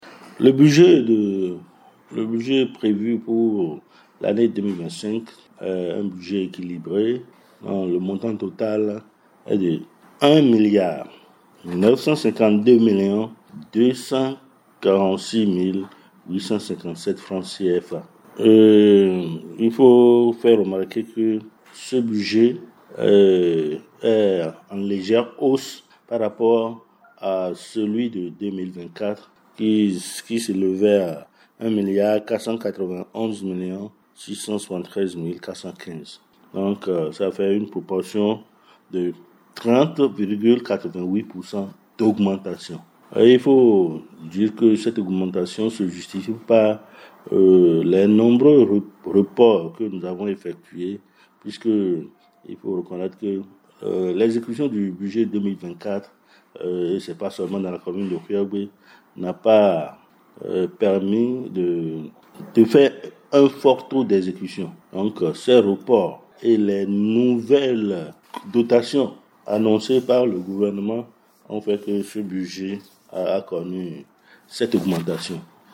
Ce budget équilibré en recettes et en dépenses a connu un léger accroissement par rapport à celui de 2024 qui s’élevait à 1 milliard 491 millions 673 mille 415 FCFA. Casimir Sossa, premier adjoint au maire de Houéyogbé évoque les raisons de cette augmentation.